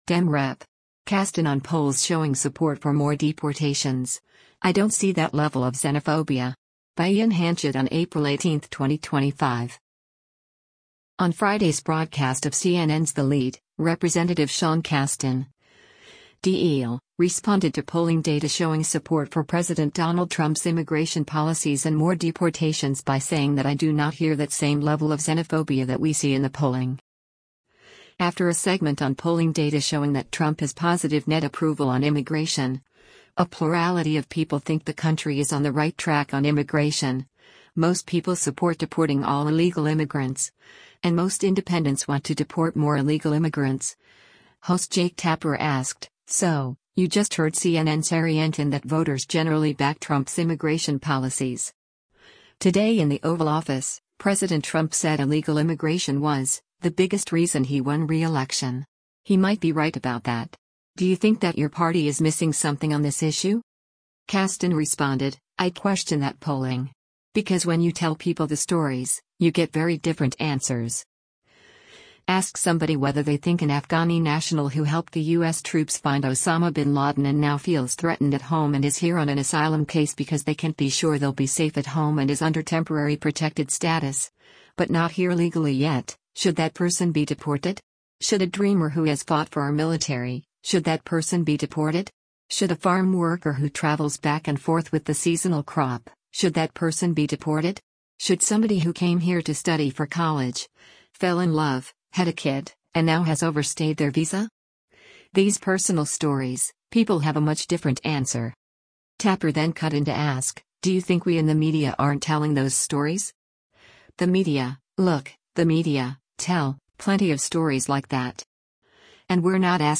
On Friday’s broadcast of CNN’s “The Lead,” Rep. Sean Casten (D-IL) responded to polling data showing support for President Donald Trump’s immigration policies and more deportations by saying that “I do not hear that same level of xenophobia that we see in the polling.”